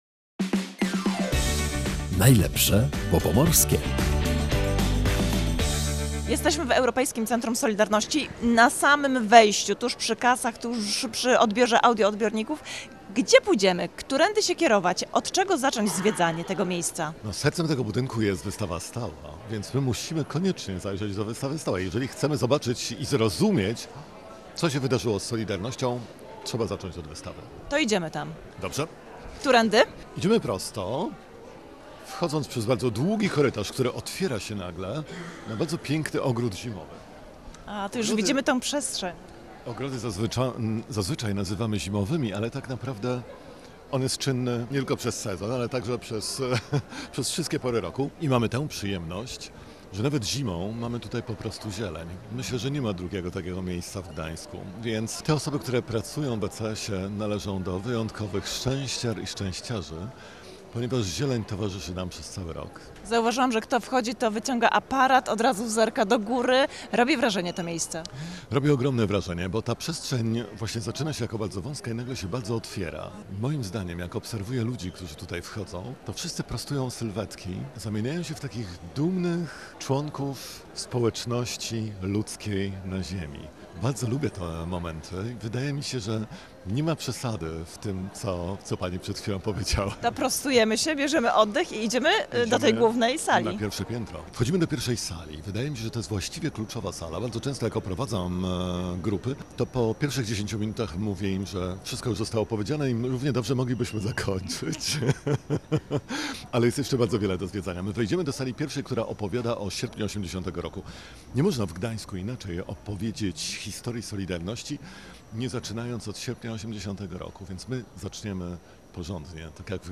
W audycji „Najlepsze, bo pomorskie” zaglądamy do miejsca, które jest wręcz obowiązkowe do zwiedzenia w Gdańsku – Europejskiego Centrum Solidarności.